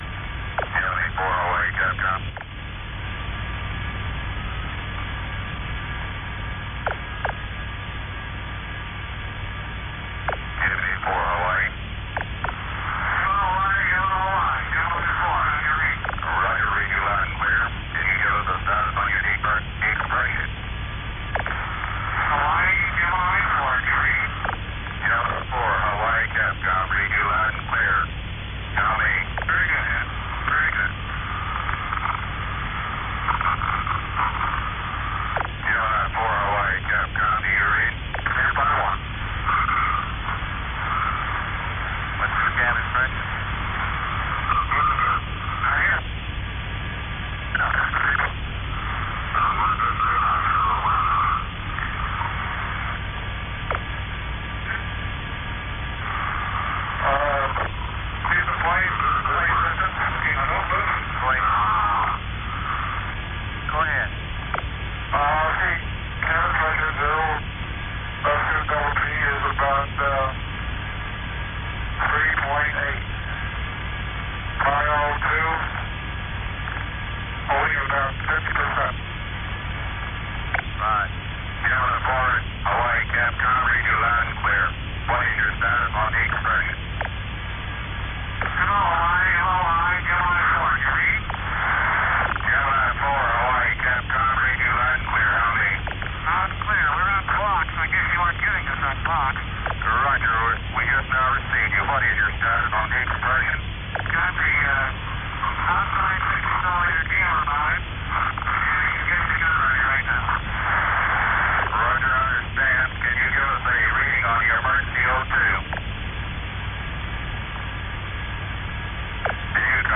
Hear the full EVA as recorded at Carnarvon Recording starts at 04:23:01 GET, with Hawaii Capcom calling Gemini IV. 16MB mp3 file , 33 minutes 41 seconds. Recorded at Carnarvon.
Gemini_IV_EVA_recorded_at_Carnarvon.mp3